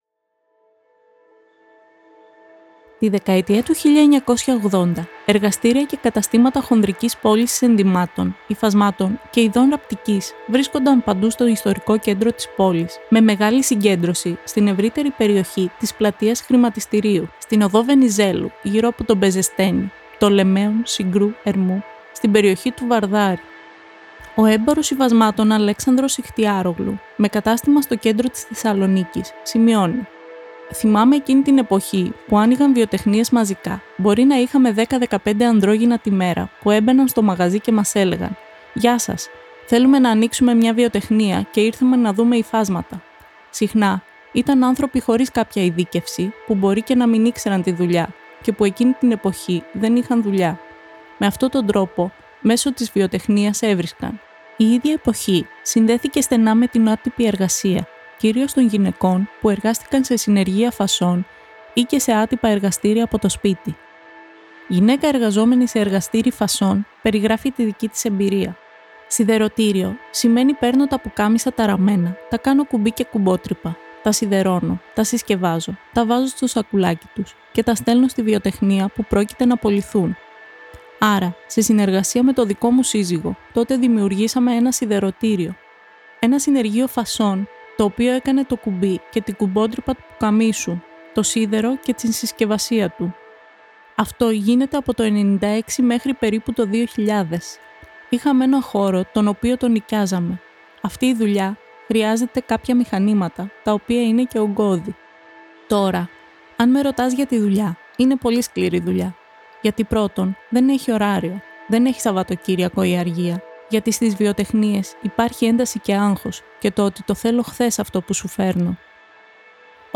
Excerpt of an interview